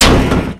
velox / Assets / sounds / collisions / car_heavy_4.wav
car_heavy_4.wav